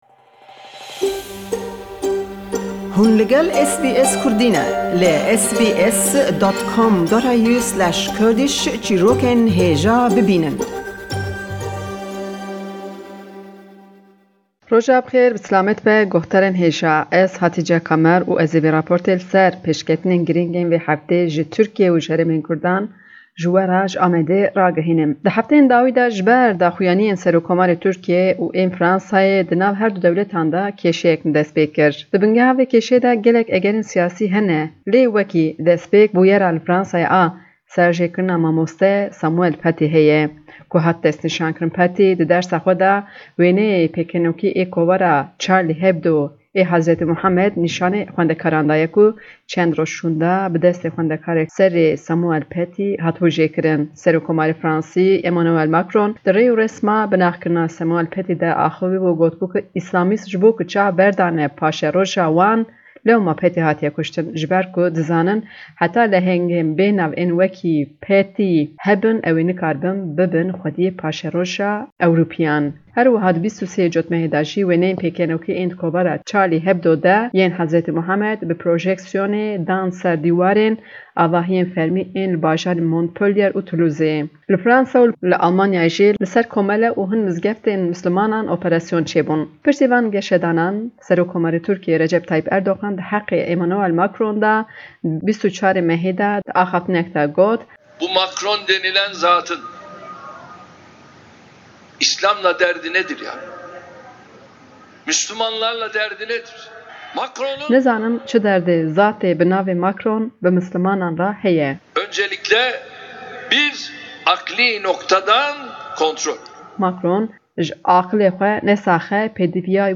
Raporta vê heftê ji Amedê li ser girjîya di nav Fransa û Tirkiyê de ye ku piştî serjêkirina mamosteyê Fransî Samuel Paty geş bû. Serokomarê Tirkiye Erdogan jibo daxuyaniyên Serokomarê Fransî Emmanuel Macron got ew dijminê îslamê ye û divê ji aqilê xwe derman bibe.